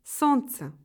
Trois consonnes sont toujours dures: Ц, Ж, Ш